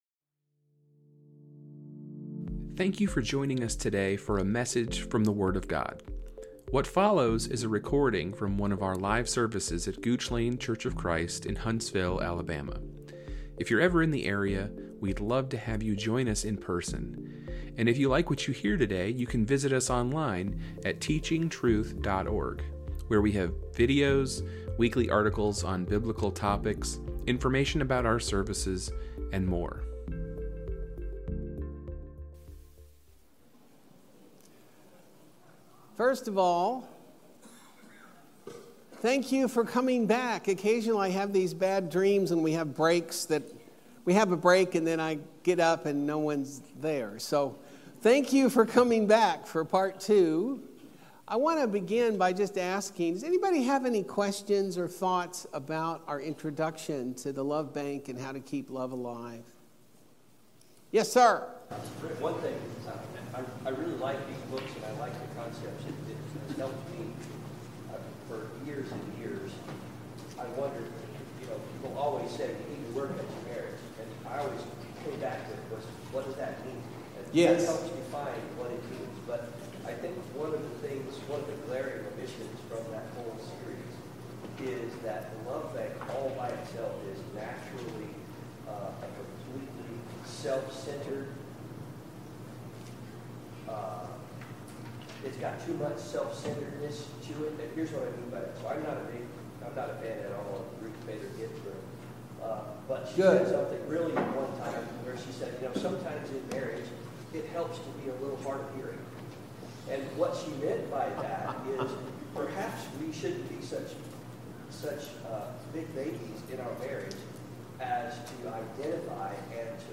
Lesson 4 of our 8-part gospel meeting